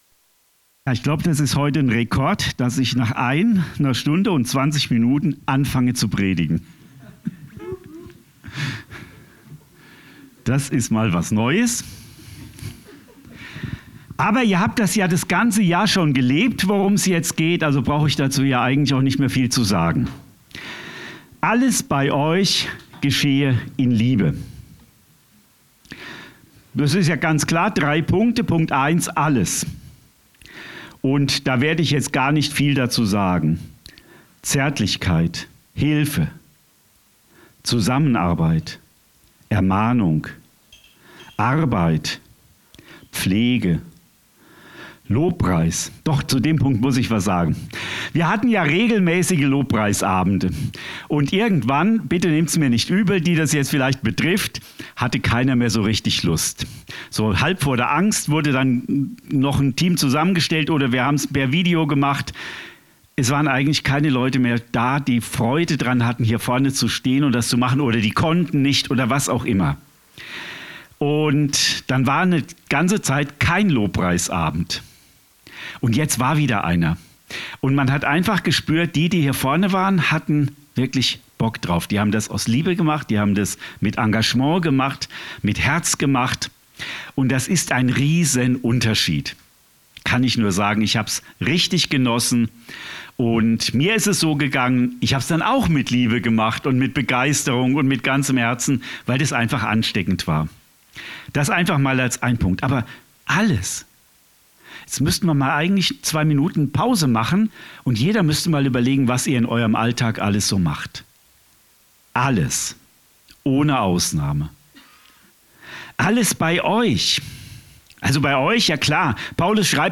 Predigt vom 31. Dezember 2024 – Süddeutsche Gemeinschaft Künzelsau